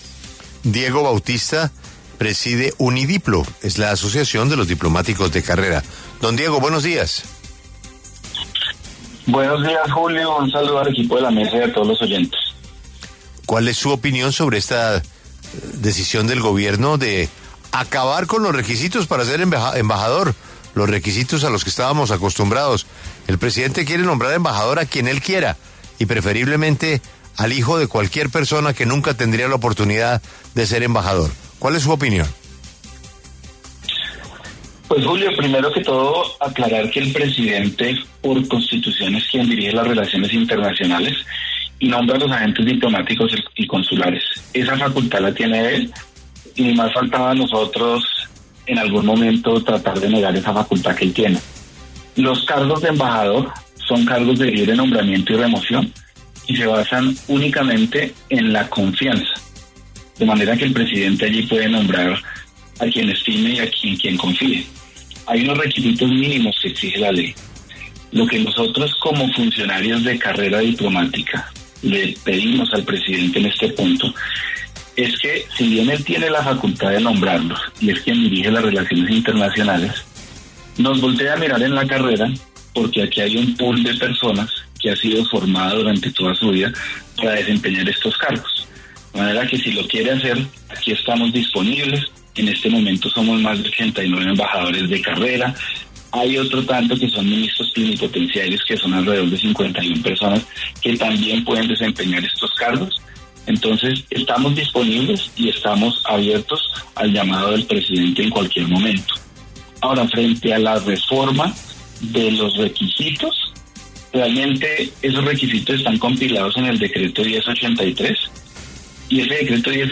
habló en los micrófonos de La W sobre la decisión del Gobierno de acabar con los requisitos para ser embajador.